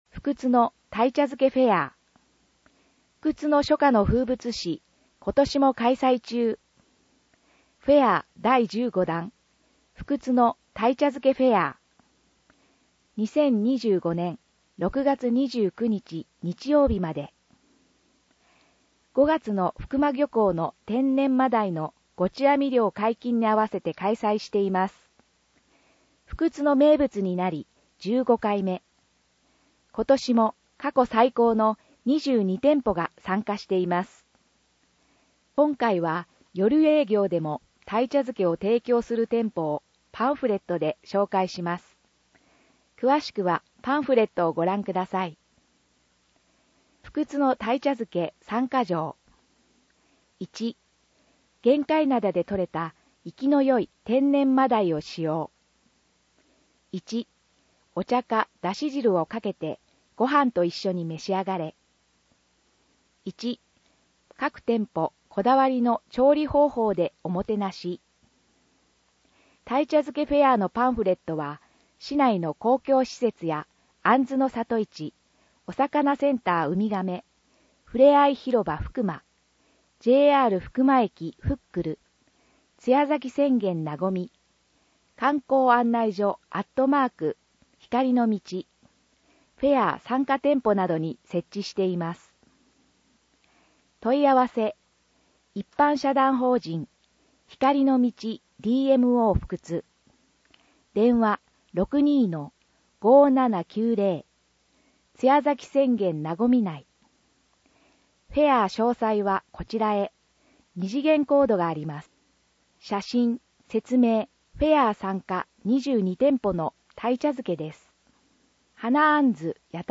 音訳ボランティアふくつの皆さんが、毎号、広報ふくつを音訳してくれています。